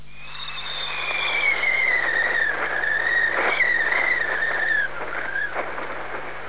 wind20.mp3